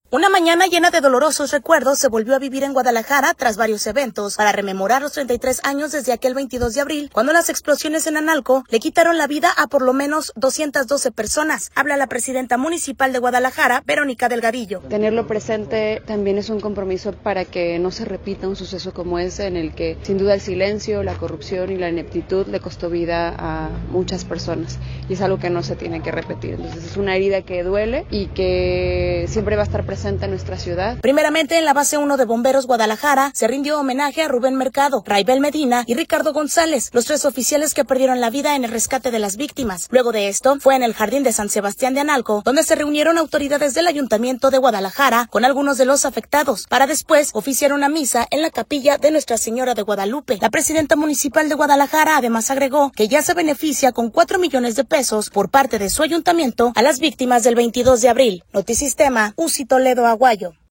Una mañana llena de dolorosos recuerdos se volvió a vivir en Guadalajara tras varios eventos para rememorar los 33 años desde aquel 22 de abril cuando las explosiones en Analco le quitaron la vida a por lo menos a 212 personas. Habla la Presidenta municipal de Guadalajara, Verónica Delgadillo.